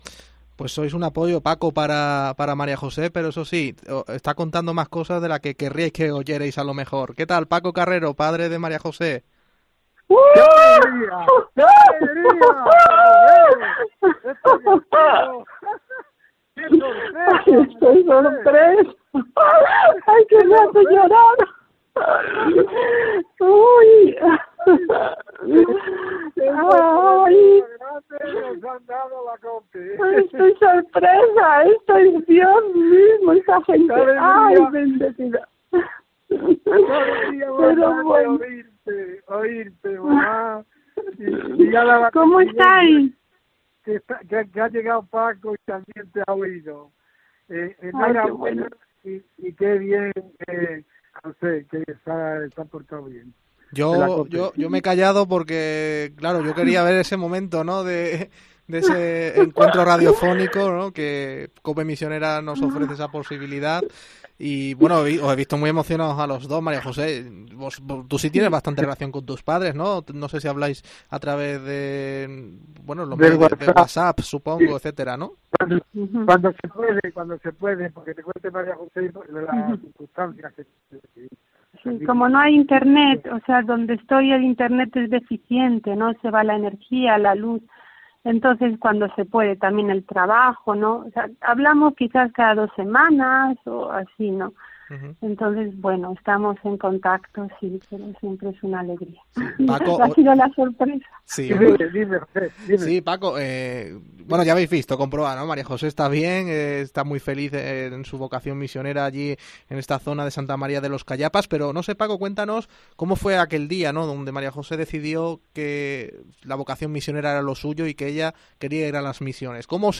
Escucha la voz de su padre por sorpresa en 'COPE misionera' y la reacción de esta misionera fue indescriptible